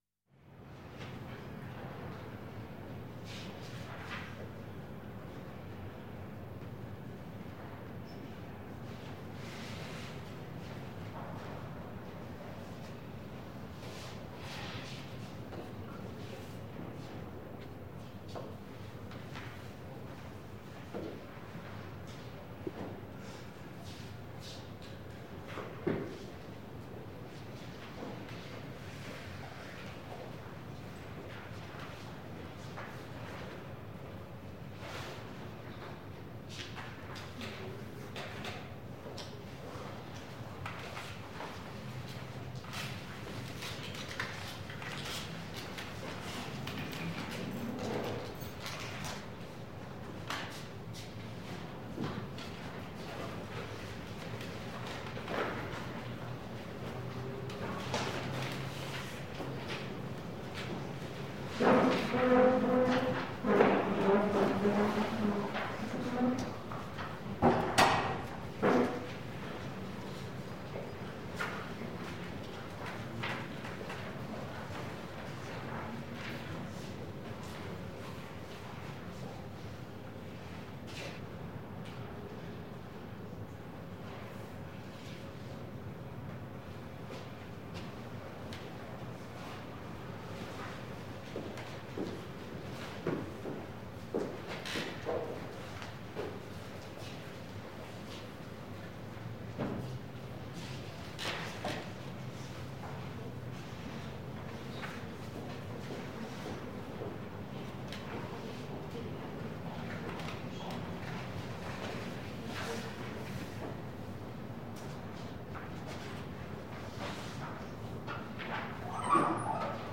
На этой странице собраны звуки библиотеки: тихий шелест страниц, шаги между стеллажами, отдаленные голоса читателей.
Тихий звук библиотечного покоя